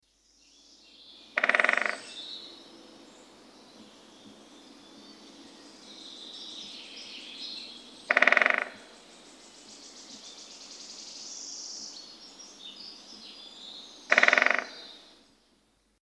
pic_epeiche.mp3